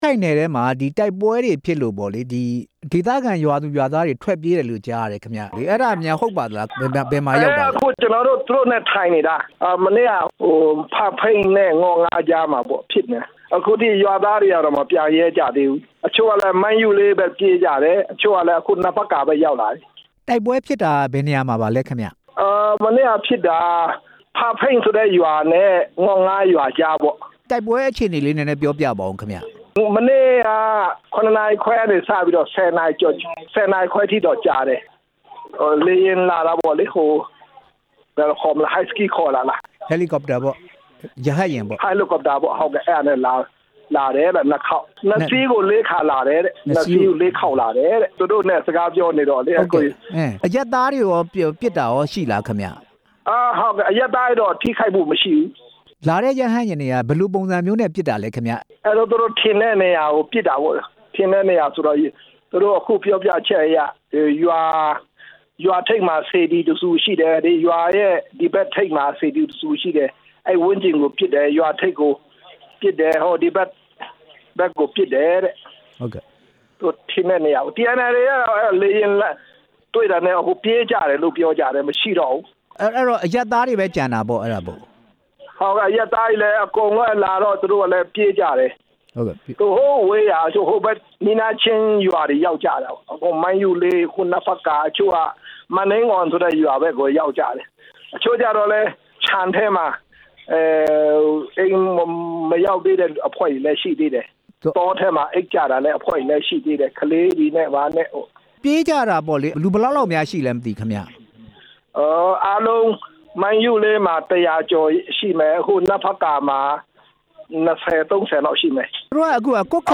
ကွတ်ခိုင်မြို့နယ်က စစ်ပြေးဒုက္ခသည်တွေအကြောင်း မေးမြန်းချက်